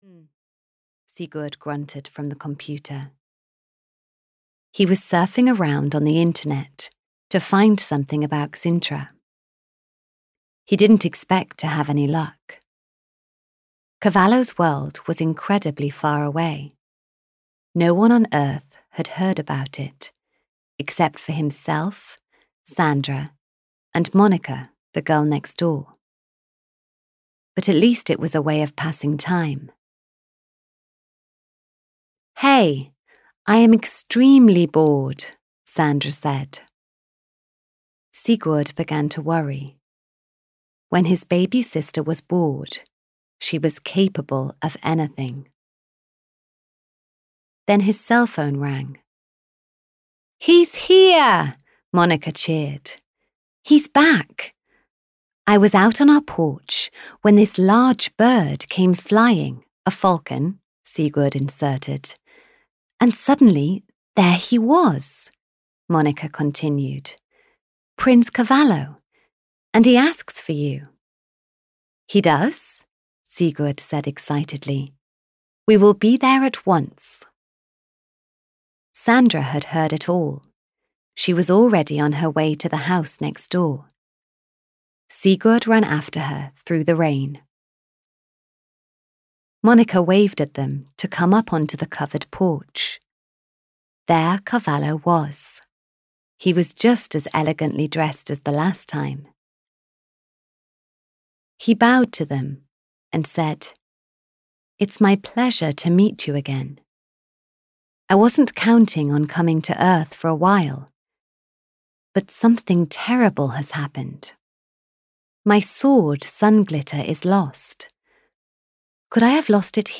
Audio knihaThe Magical Falcon 2 - The Falcon in Chains (EN)
Ukázka z knihy